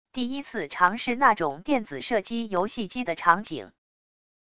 第一次尝试那种电子射击游戏机的场景wav音频